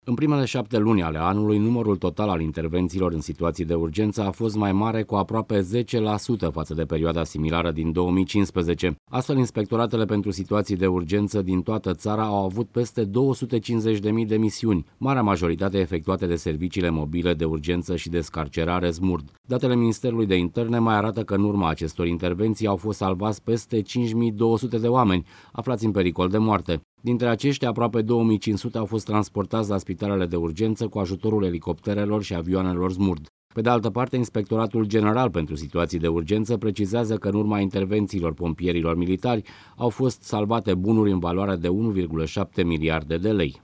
Cifrele au fost prezentate la Ministerul de Interne .